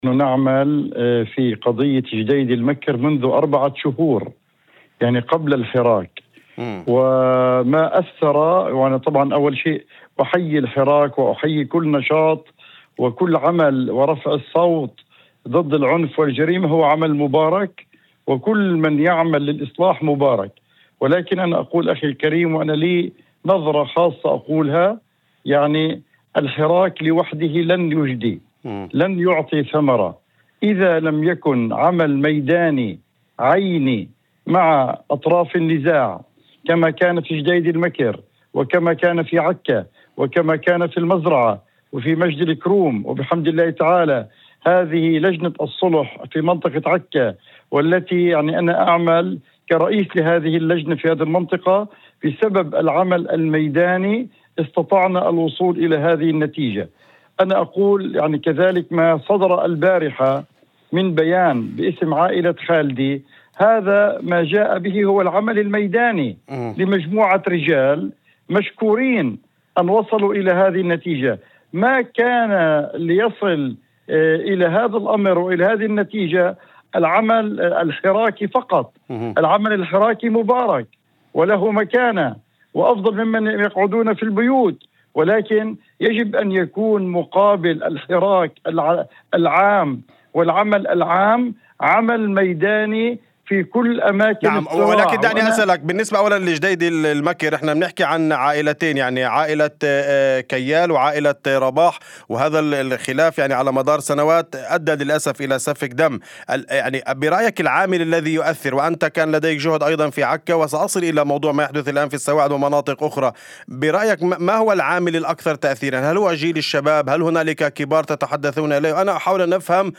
وأضاف في مداخلة هاتفية لبرنامج "أول خبر"، أن الحراك العام ورفع الصوت ضد العنف "مهم ومبارك"، لكنه غير كافٍ إذا لم يترافق مع تدخل ميداني منظم ومستمر.